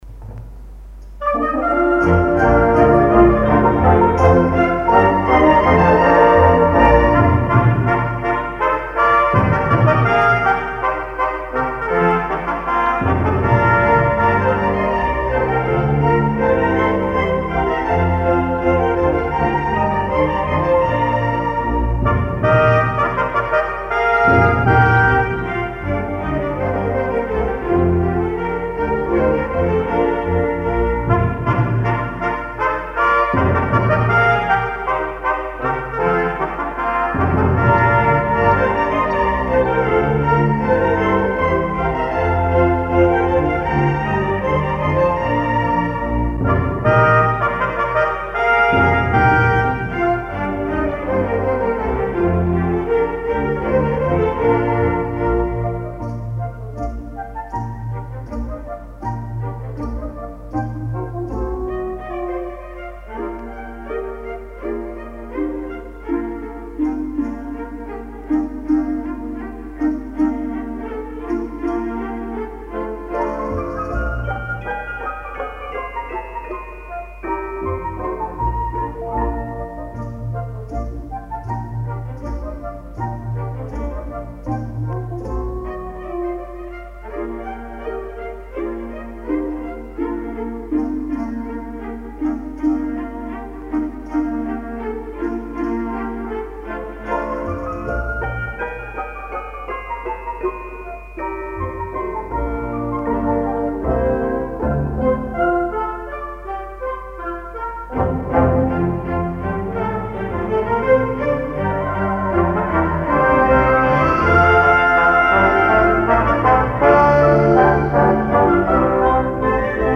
Here is Friel’s interpretion of “Morgan Magan” , a tune going back to around 1718. Friel orchestrated it in the early 1960s.